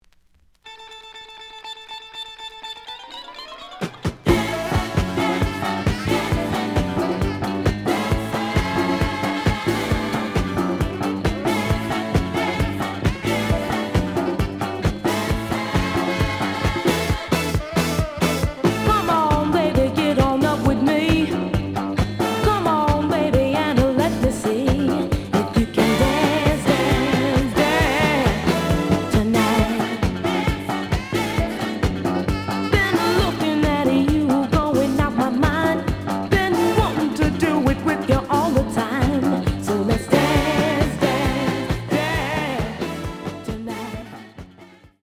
The audio sample is recorded from the actual item.
●Genre: Disco
Edge warp.